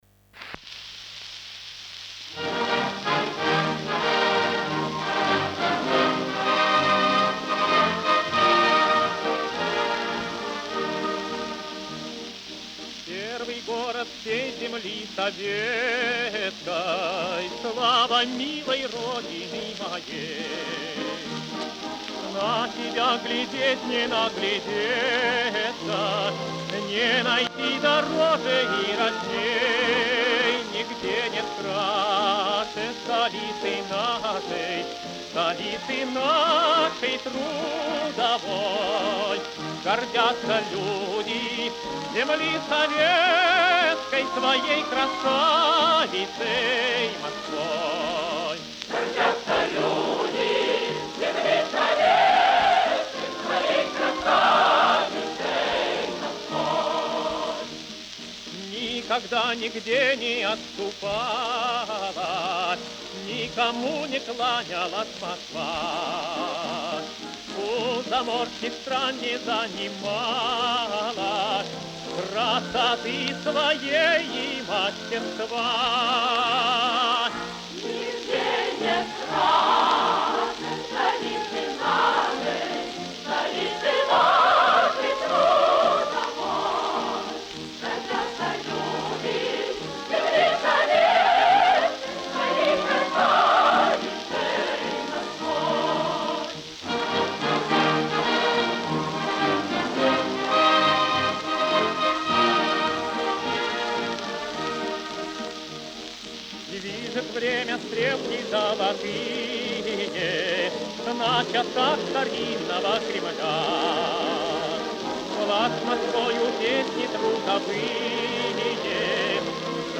Пластинка и запись 1950 года.